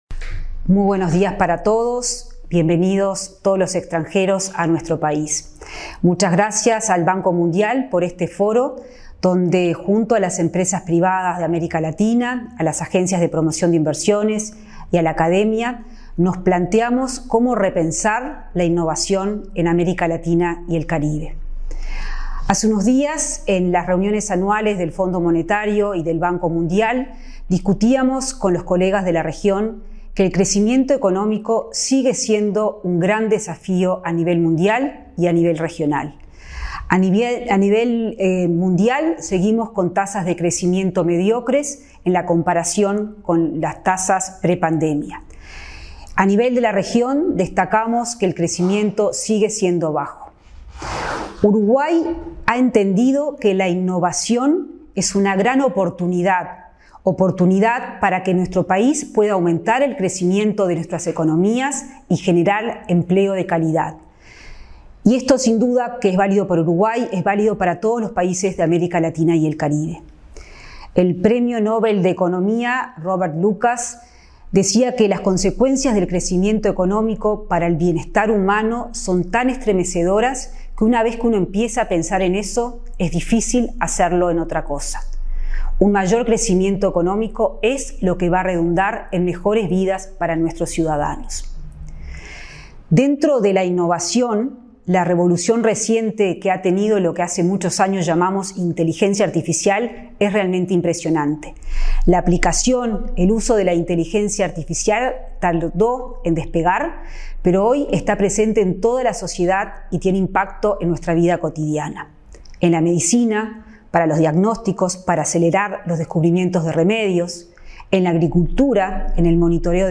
Palabras de la ministra de Economía, Azucena Arbeleche
Palabras de la ministra de Economía, Azucena Arbeleche 13/11/2024 Compartir Facebook X Copiar enlace WhatsApp LinkedIn La titular del Ministerio de Economía y Finanzas (MEF), Azucena Arbeleche, participó, a través de un video, en la jornada ¿Cómo Repensar la Innovación en América Latina y el Caribe?, organizada por el Banco Mundial y el MEF. El evento se desarrolló en el Laboratorio Tecnológico del Uruguay.